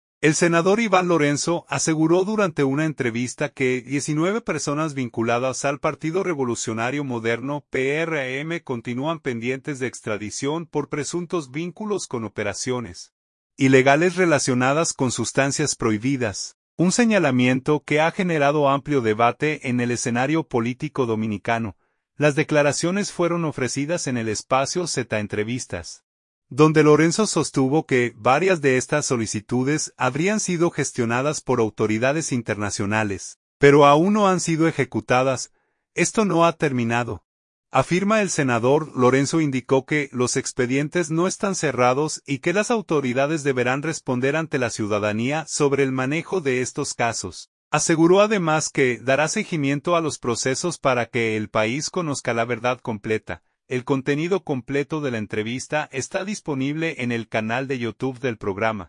SANTO DOMINGO.– El senador Iván Lorenzo aseguró durante una entrevista que 19 personas vinculadas al Partido Revolucionario Moderno (PRM) continúan pendientes de extradición por presuntos vínculos con operaciones ilegales relacionadas con sustancias prohibidas, un señalamiento que ha generado amplio debate en el escenario político dominicano.